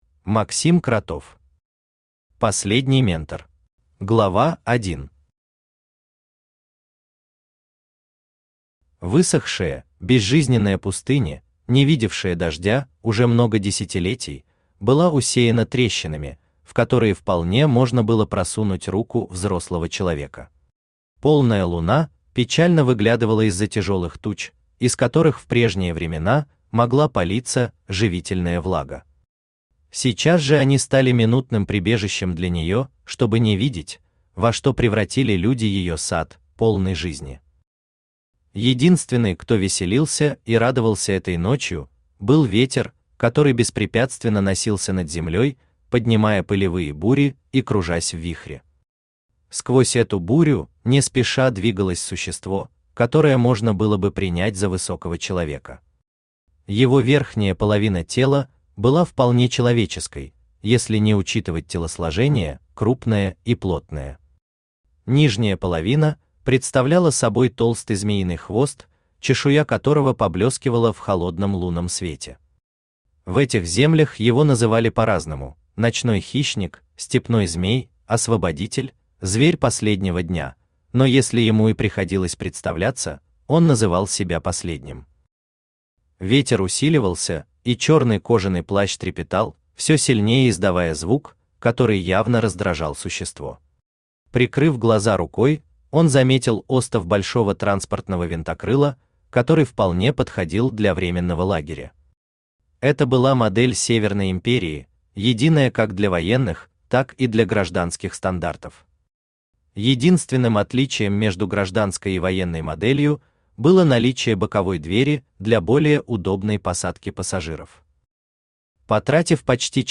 Аудиокнига Последний Ментор | Библиотека аудиокниг
Aудиокнига Последний Ментор Автор Максим Александрович Кротов Читает аудиокнигу Авточтец ЛитРес.